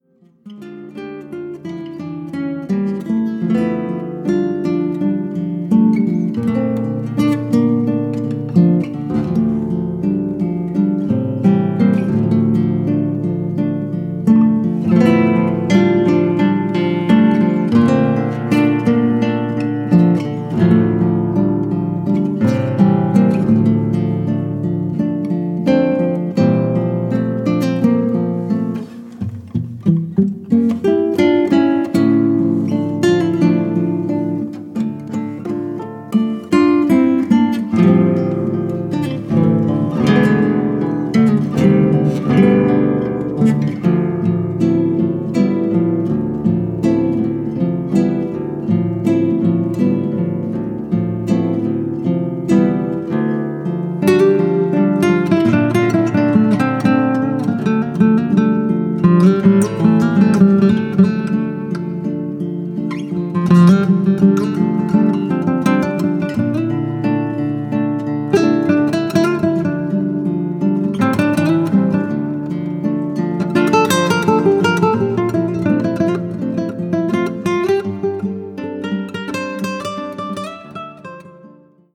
gitarre, bass, percussion
flöte
klarinette
cello